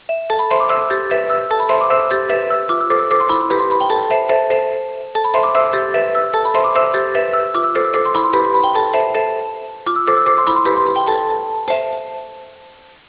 ants.wav